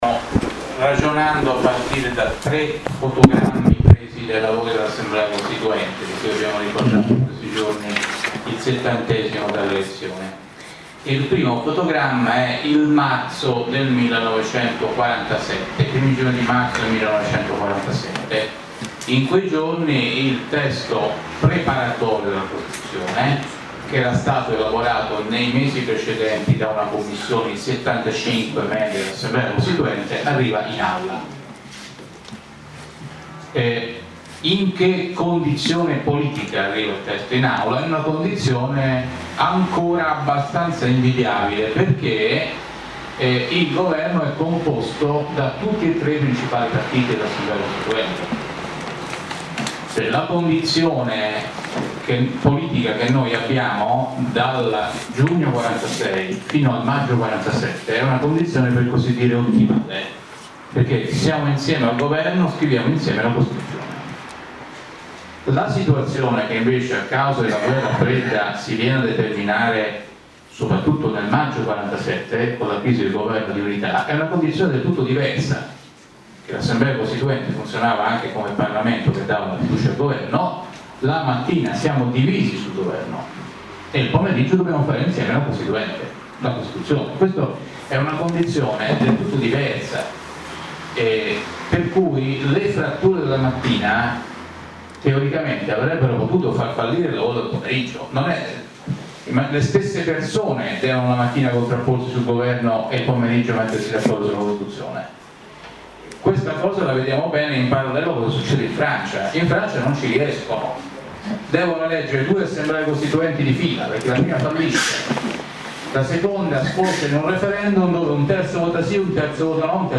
Stefano Ceccanti, un costituzionalista per il sì, incontro a Cantù (Como), 11 giugno 2016. Audio e Video dell’incontro
AUDIO dell’intervento introduttivo di STEFANO CECCANTI